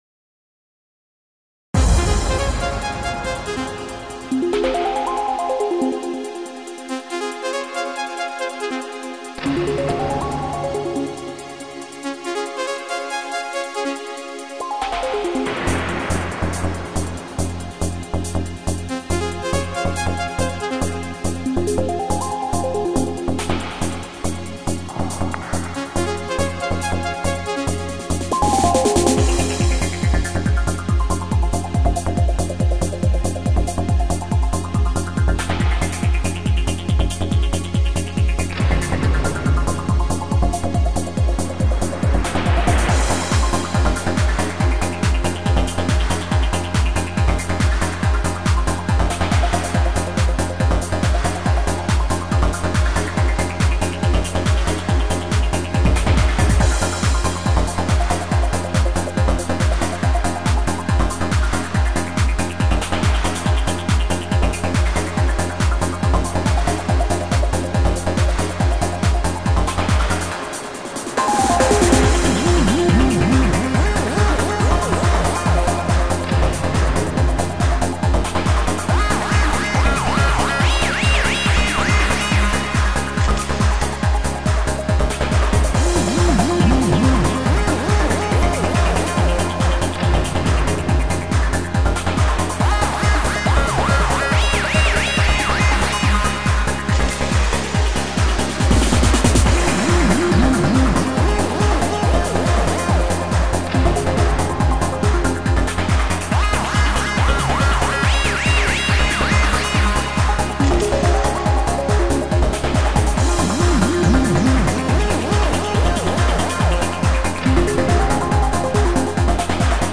• Жанр: Танцевальная